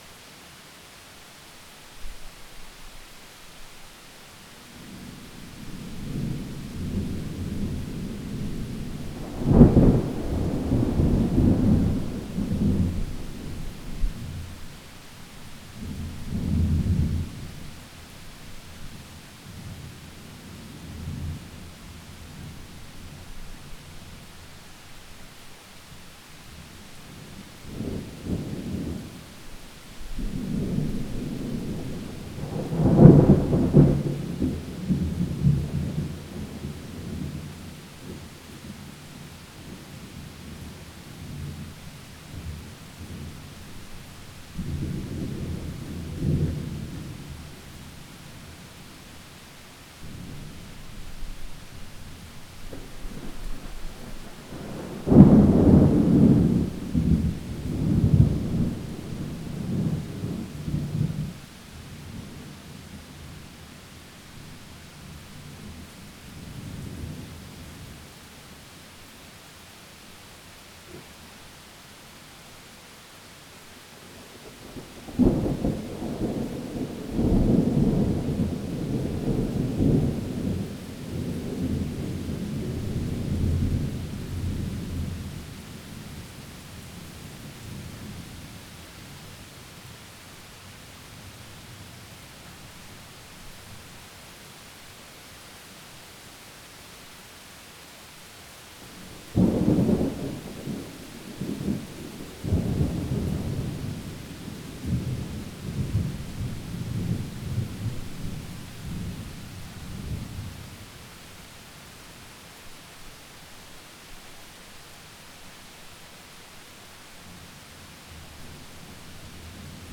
storm.wav